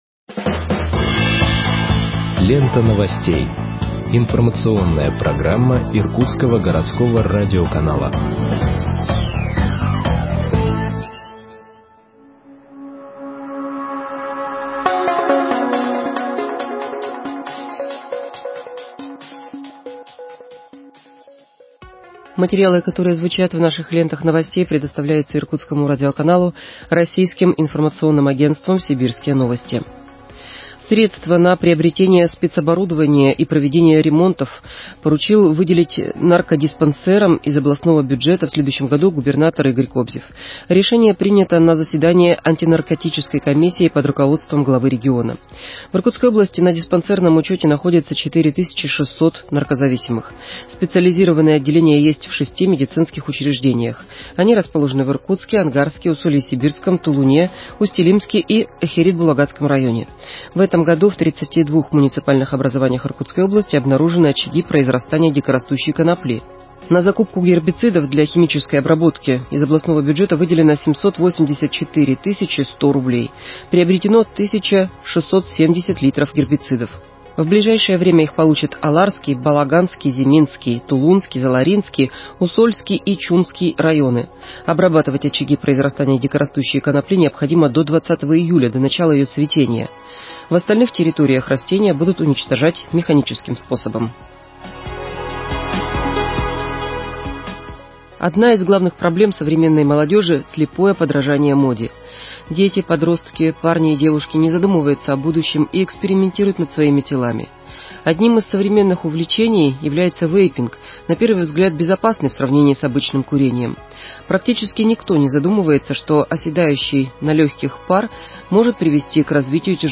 Выпуск новостей в подкастах газеты Иркутск от 25.05.2021 № 1